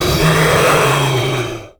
controller_idle_2.ogg